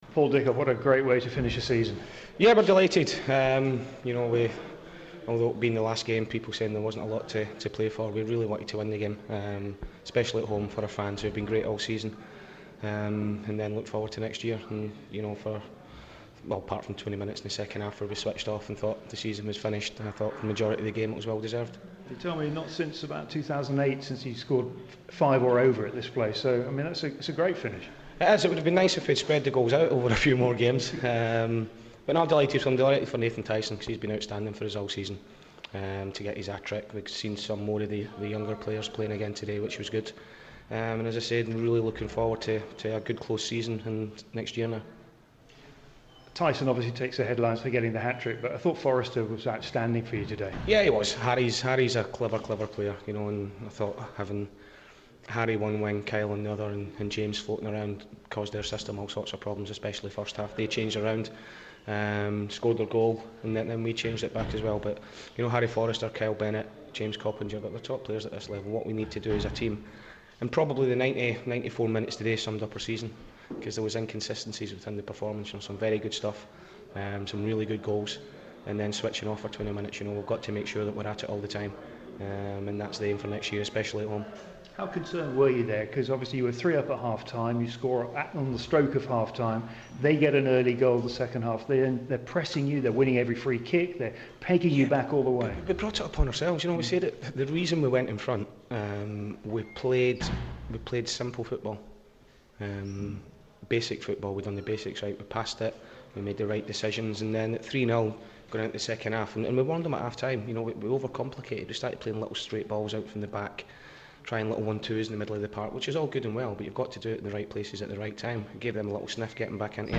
INTERVIEW: Doncaster Rovers boss Paul Dickov after his sides 5-2 win over Scunthorpe